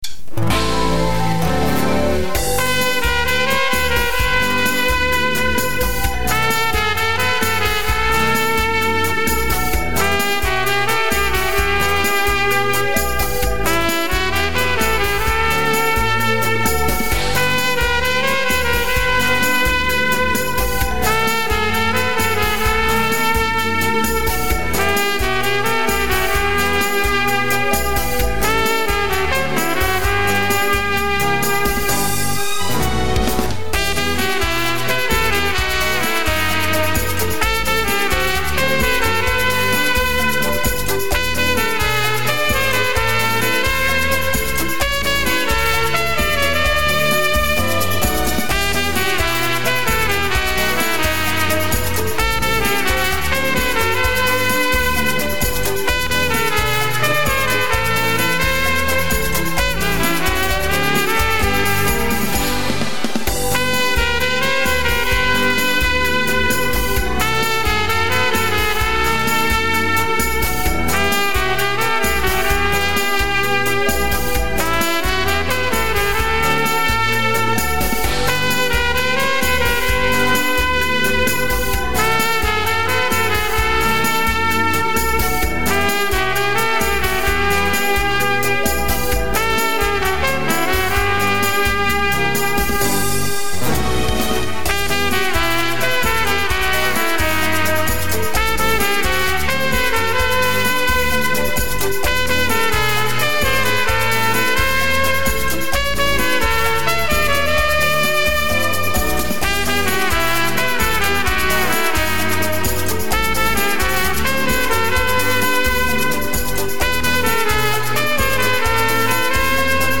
version trompette